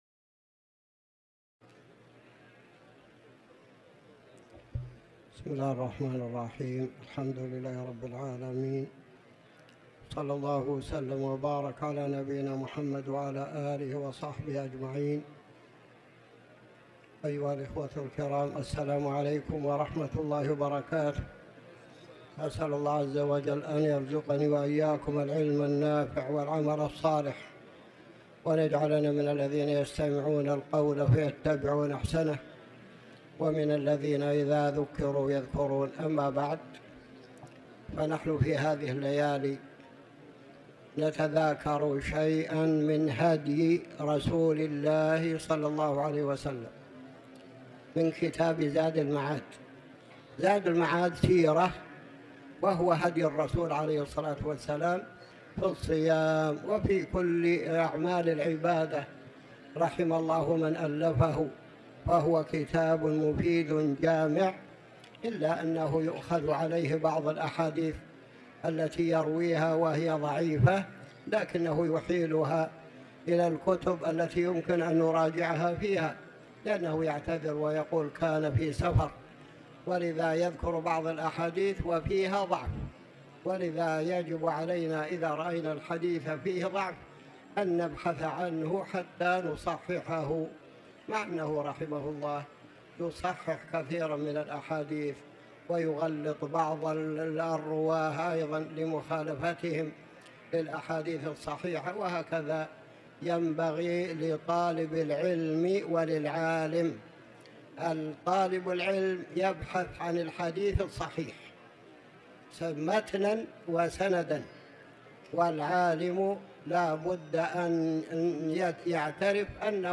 تاريخ النشر ٣ جمادى الأولى ١٤٤٠ هـ المكان: المسجد الحرام الشيخ